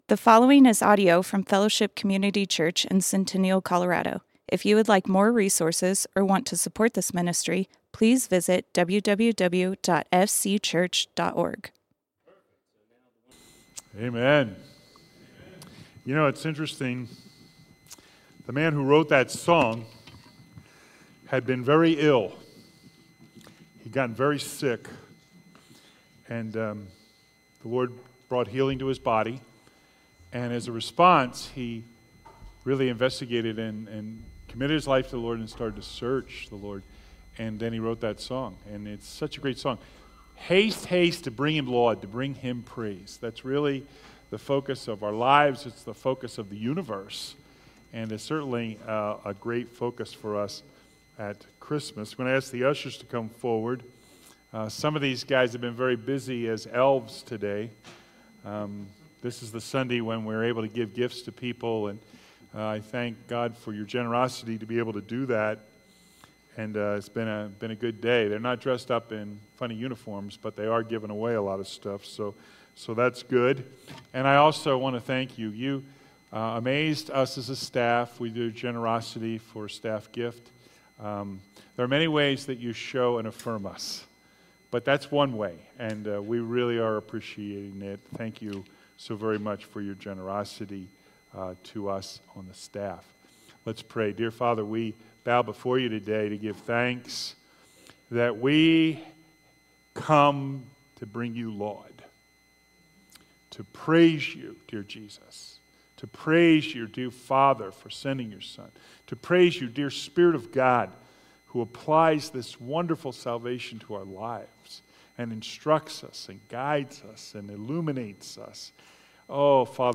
Fellowship Community Church - Sermons Why Wisemen?